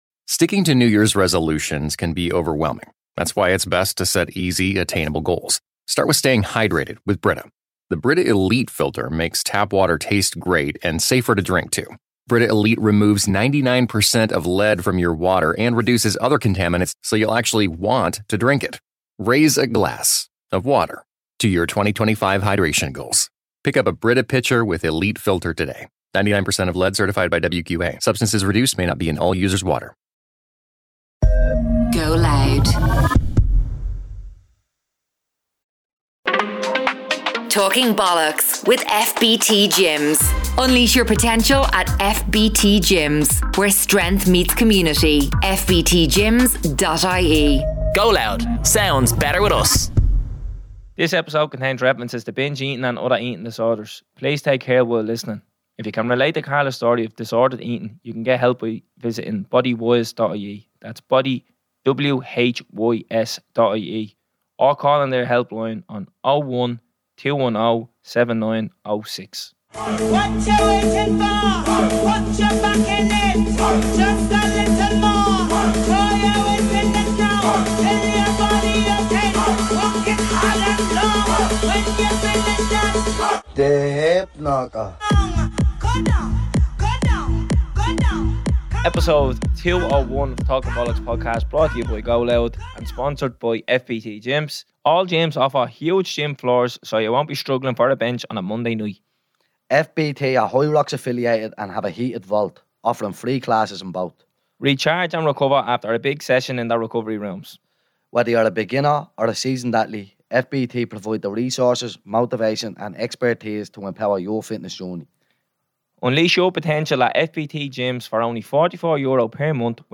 Two lads from the inner city of Dublin sitting around doing what they do best, talking bollox.